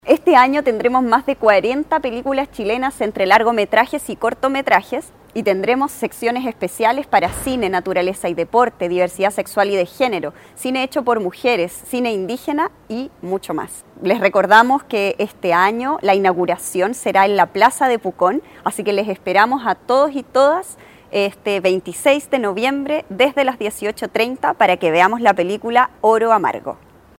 Un colorido y formal lanzamiento tuvo hace algunos días la séptima versión del Festival de Cine Rukapillan Pucón 2025, el que vivió esta jornada estelar y “en sociedad” en la Universidad de La Frontera (UFRO) en Temuco, en donde fueron convocados autoridades y prensa local, adelantando el nutrido programa de este certamen fijado entre el 26 y 30 de noviembre próximo en el campus Pucón de la entidad educativa regional.